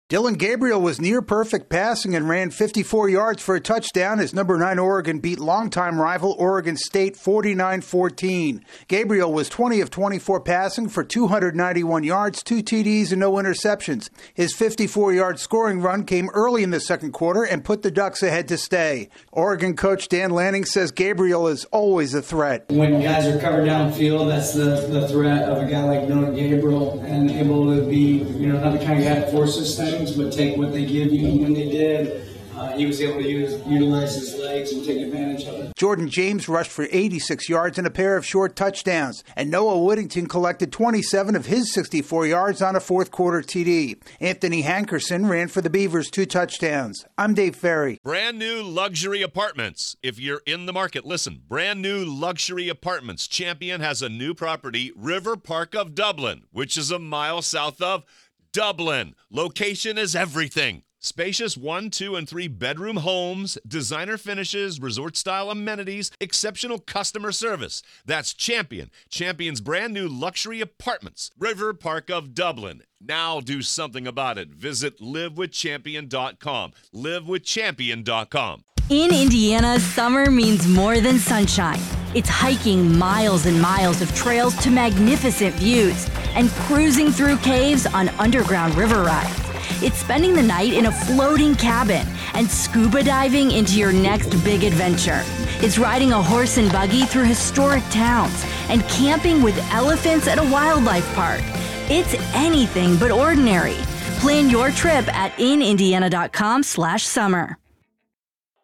Oregon clobbers its neighbor in the Ducks' first game against Oregon State since leaving the Pac-12. AP correspondent